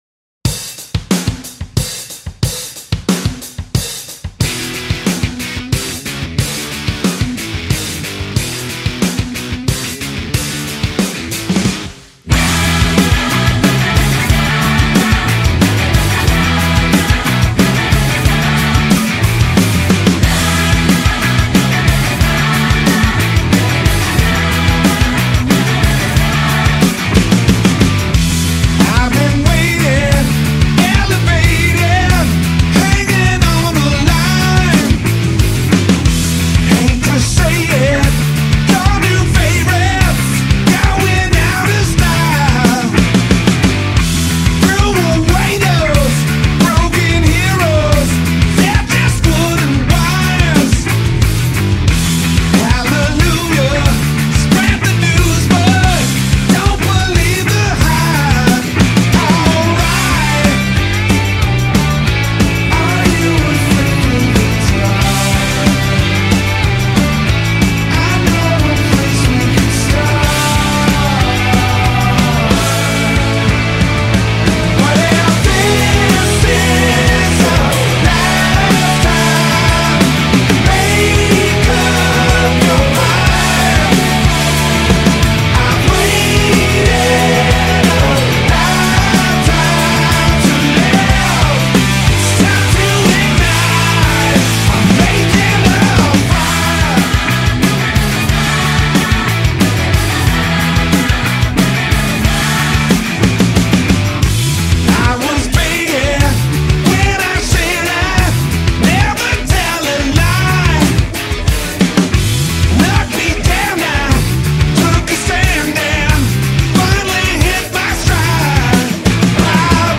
Rock, Pop-Rock, Alternative Rock